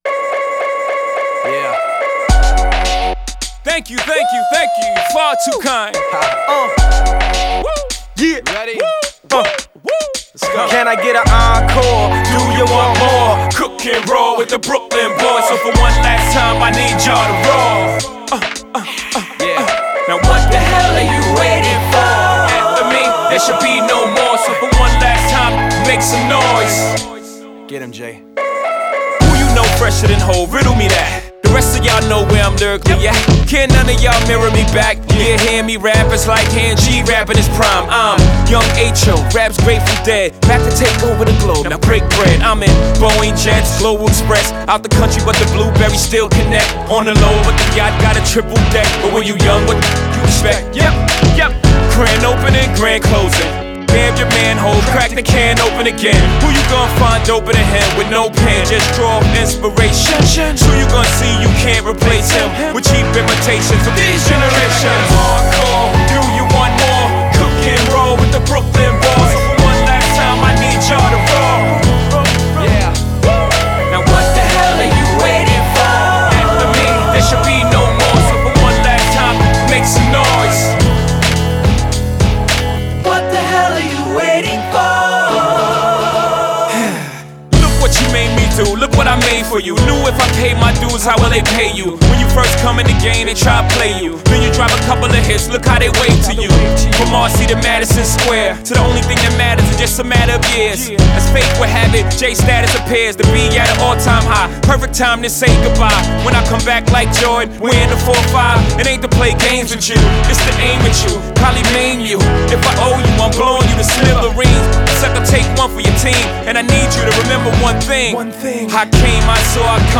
• Жанр: Alternative, Rock, Hip-Hop